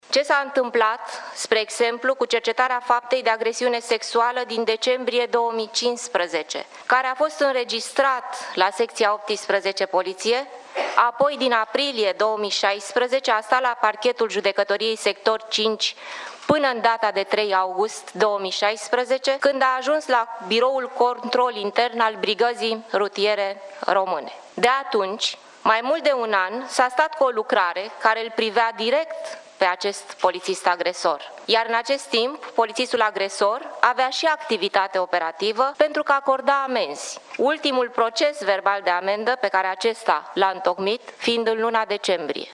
Ministrul de Interne a declarat, marţi, într-o conferinţă de presă, că a transmis premierului propunerea de schimbare din funcţie a şefului Poliţiei Române, Bogdan Despescu, care va fi înlocuit de Cătălin Ioniţă.